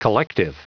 Prononciation du mot collective en anglais (fichier audio)
Prononciation du mot : collective